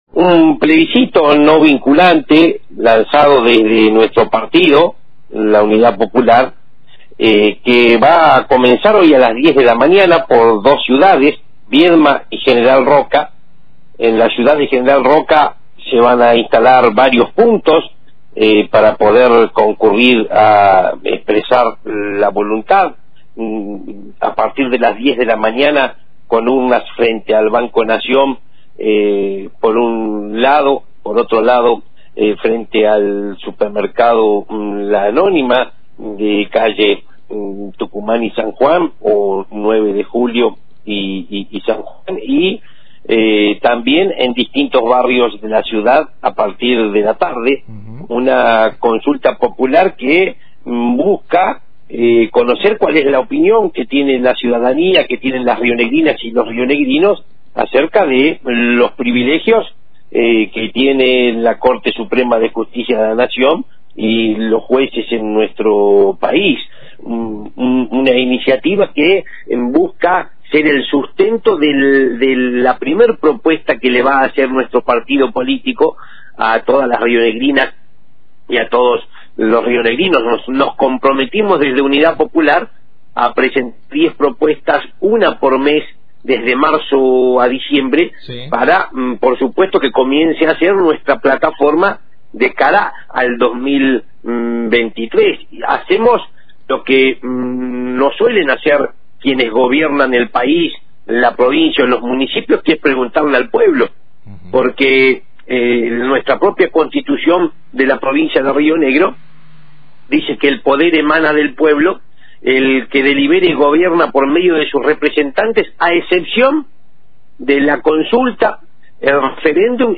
conversó esta mañana con Antena Libre y valoró la función del Consejo de la Magistratura en la regulación del accionar de los jueces: